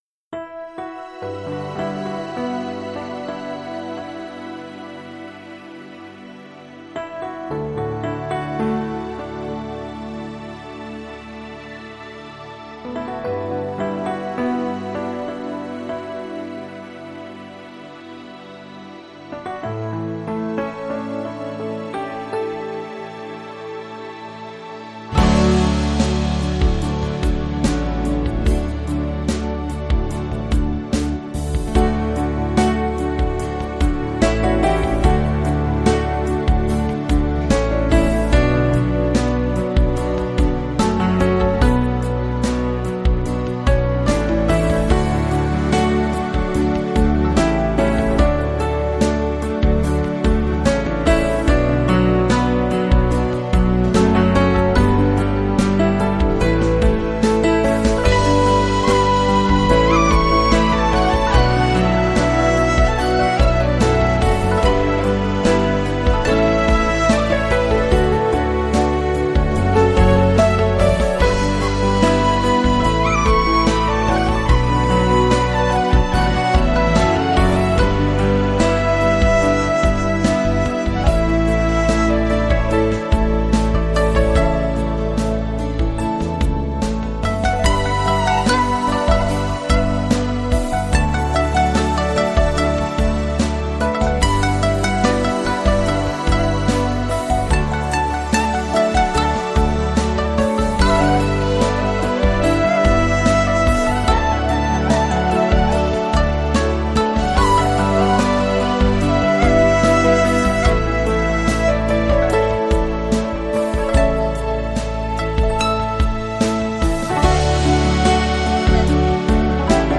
只有回归音乐本质的旋律
新世纪音乐